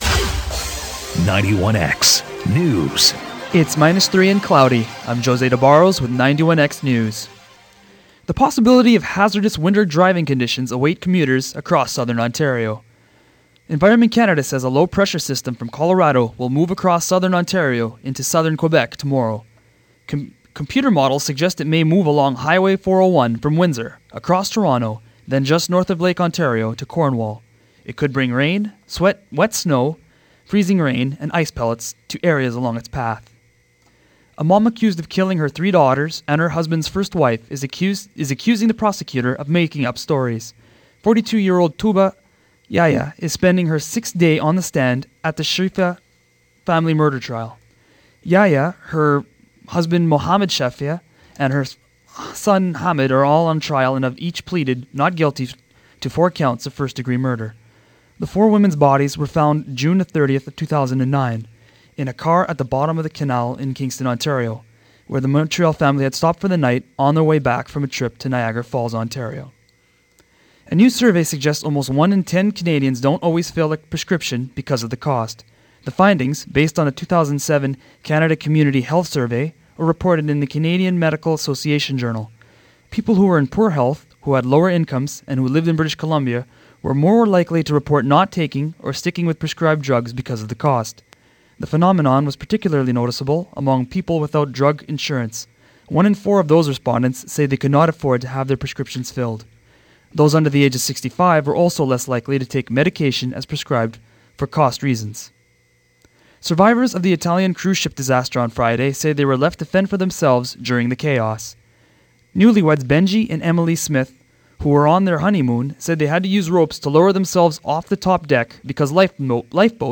91X Newscast January 16 – 1pm